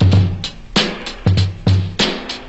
Loops, breaks